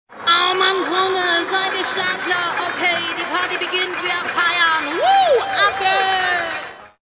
Achterbahn start Show begin/fasten your seatbelts (woman)